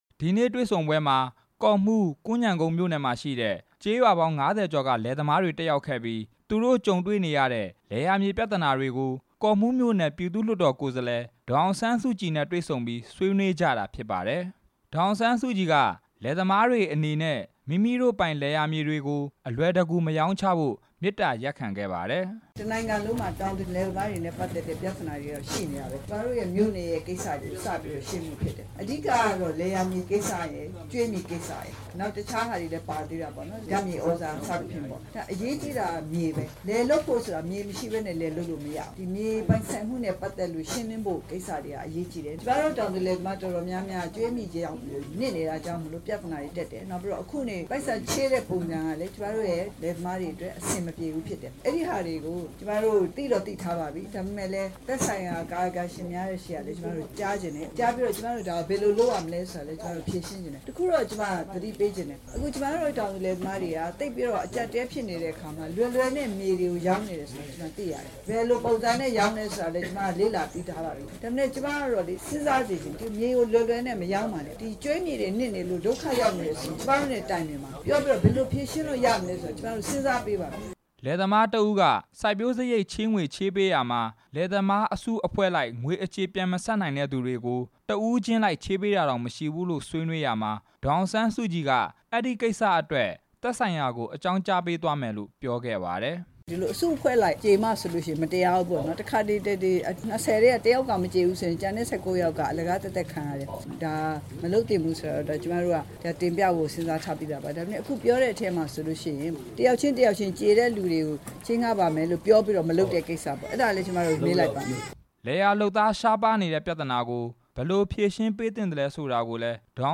ကော့မှူးမြို့နယ် အမျိုးသားဒီမိုကရေစီအဖွဲ့ချုပ်ရုံးမှာ ဒီနေ့ မနက်ပိုင်းကကျင်းပတဲ့ တွေ့ဆုံပွဲမှာ ဒေါ်အောင်ဆန်း စုကြည်က အဲဒီလို မေတ္တာရပ်ခံခဲ့တာ ဖြစ်ပါတယ်။